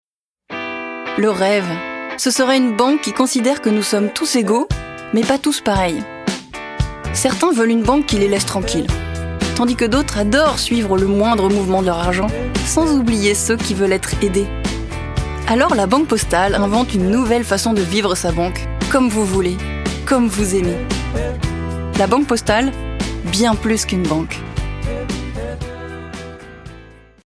Ceci est une maquette.
Des voix-off